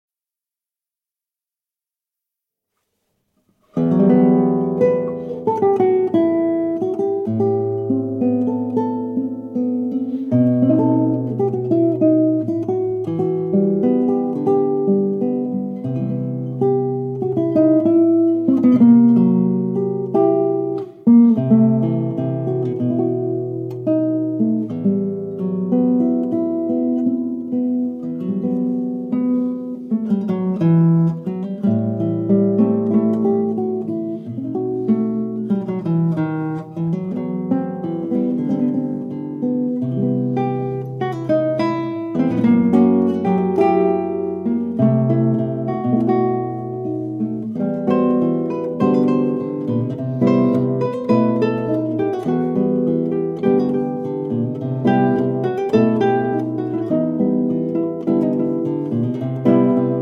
Duo works from South America
Guitar